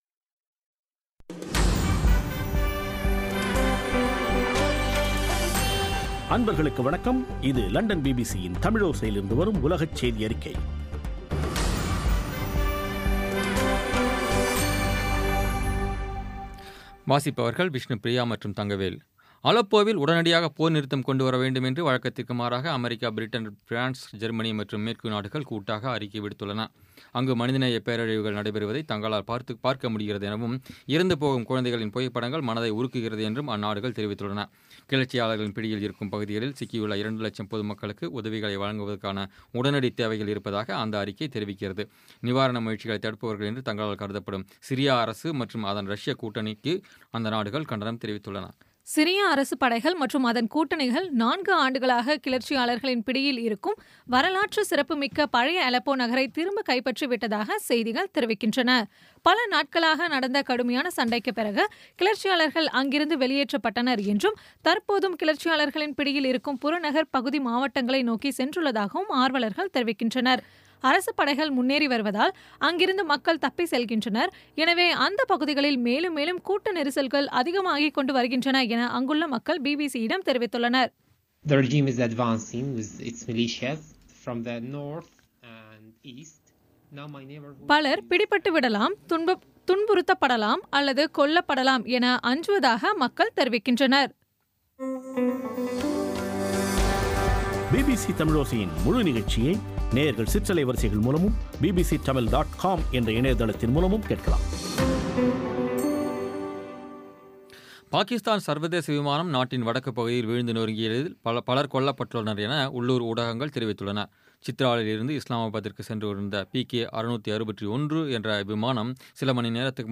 பி பி சி தமிழோசை செய்தியறிக்கை (07/12/16)